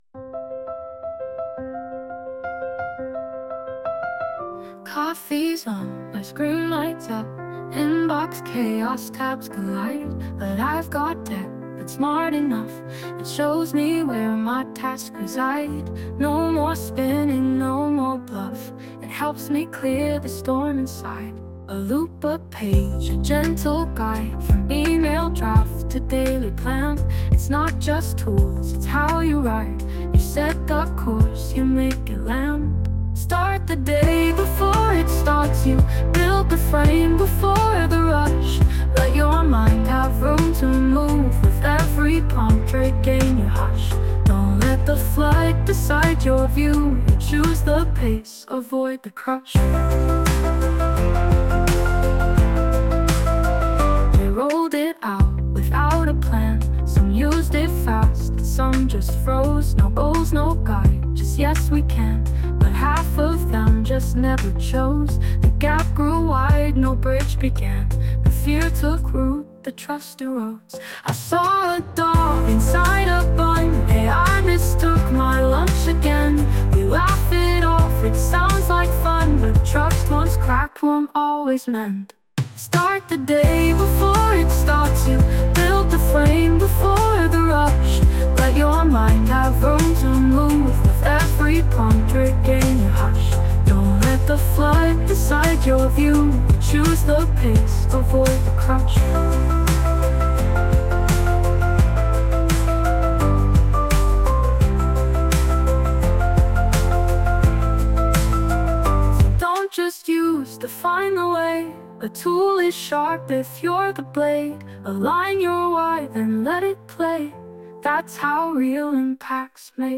Dit lied is volledig met AI gegenereerd. De teksten zijn afkomstig van de interviews van aflevering 9.